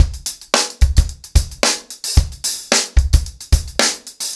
TupidCow-110BPM.39.wav